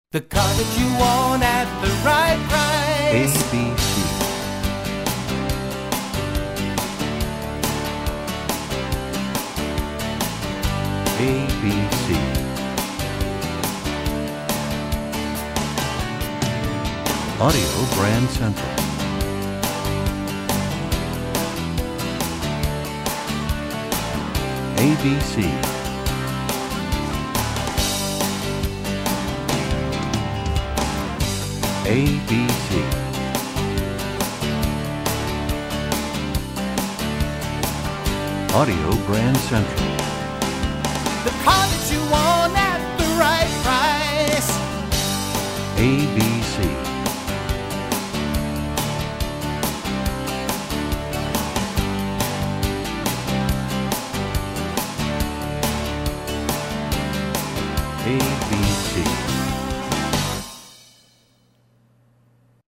MCM Category: Ad Jingles